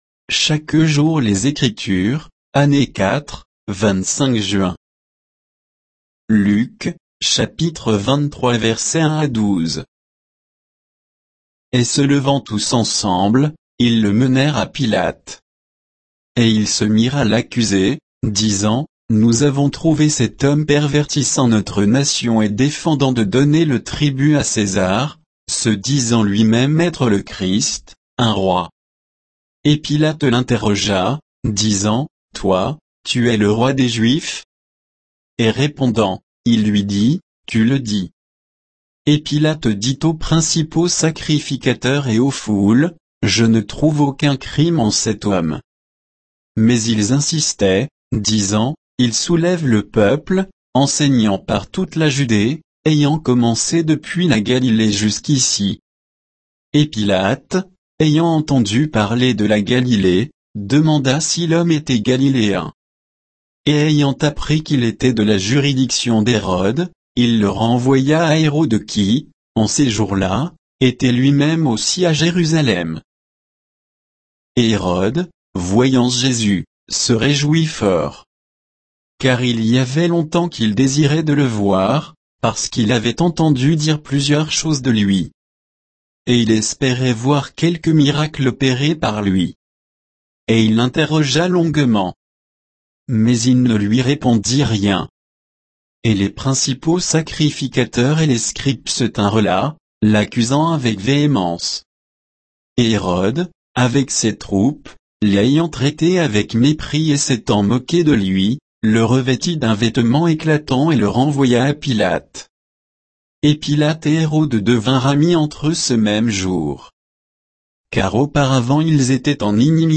Méditation quoditienne de Chaque jour les Écritures sur Luc 23, 1 à 12